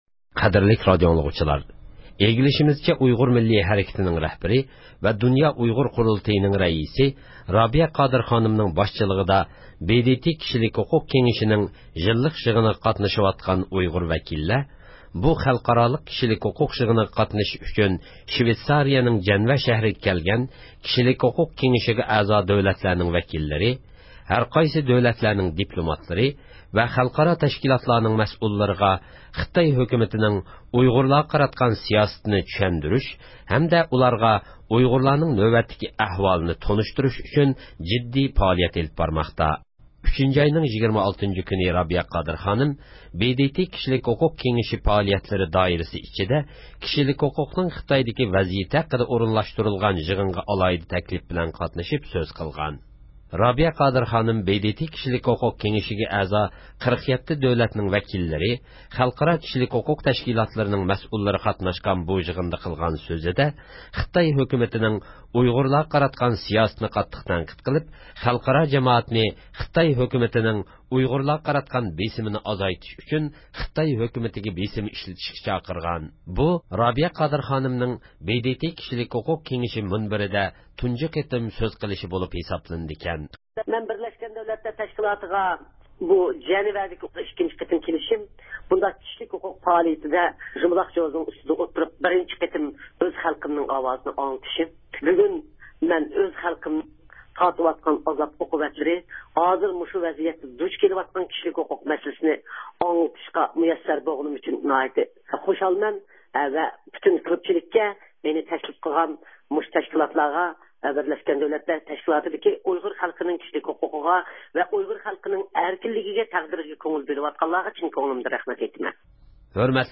رابىيە قادىر خانىم ب د ت نىڭ كىشىلىك ھوقۇق يىللىق يىغىنىدا سۆز قىلدى – ئۇيغۇر مىللى ھەركىتى
بۇ رابىيە قادىر خانىمنىڭ ب د ت كىشىلىك ھوقۇق كېڭىشى مۇنبىرىدە تۇنجى قېتىم سۆز قىلىشى بولۇپ ھېسابلىنىدىكەن.